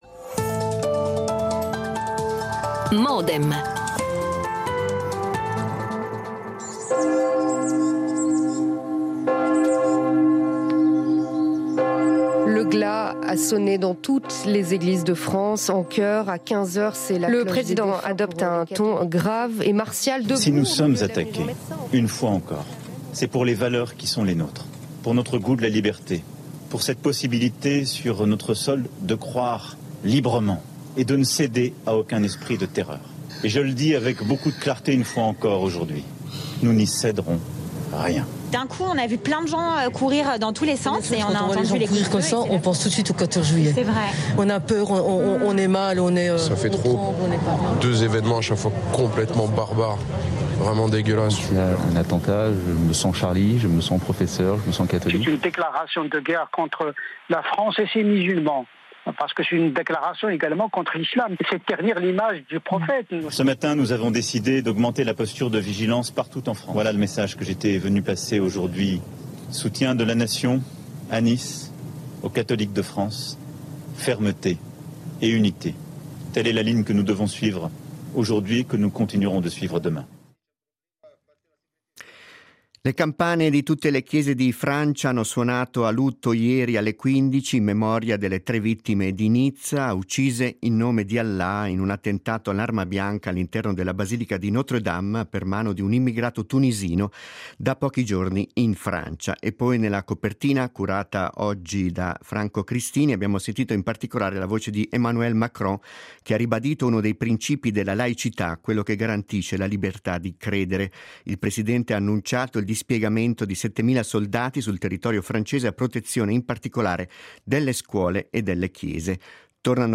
Discussione con:
L'attualità approfondita, in diretta, tutte le mattine, da lunedì a venerdì